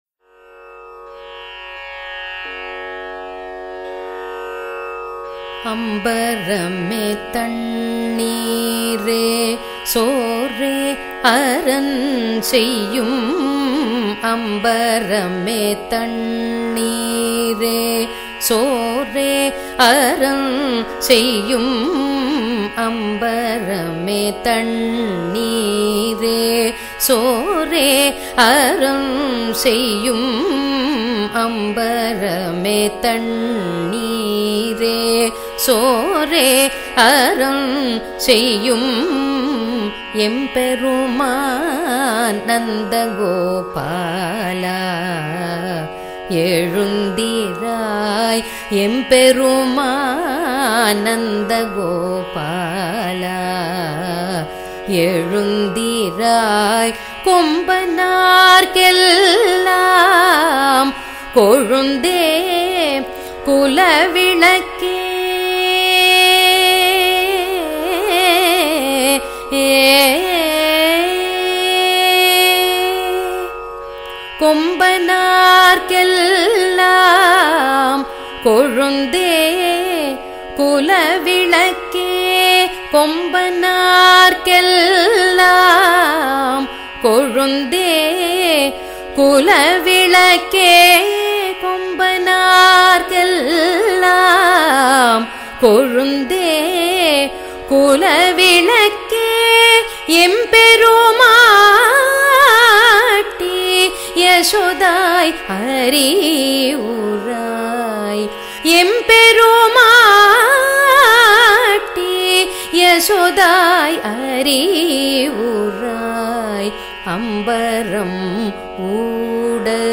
kalyANi
khanda chAppu